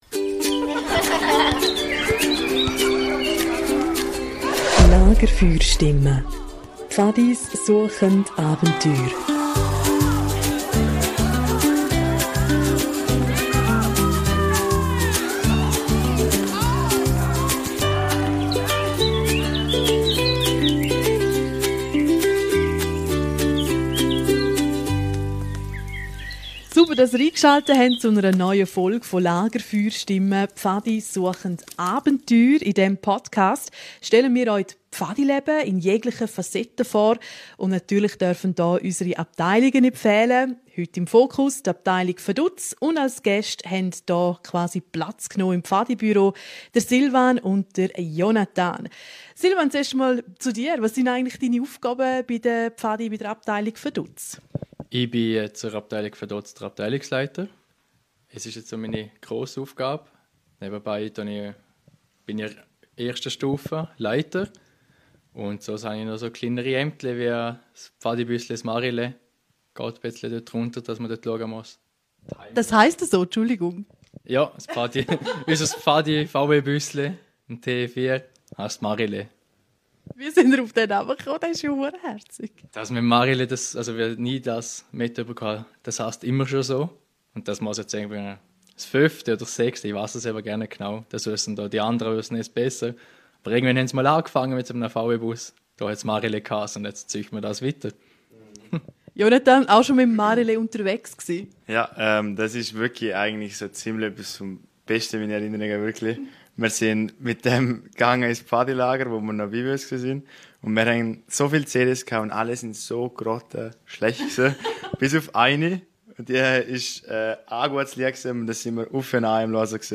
Ein informatives Gespräch mit vielen Lachern, persönlichen Geschichten und auch ernst gemeinten Zukunftswünschen für die Abteilung Vaduz.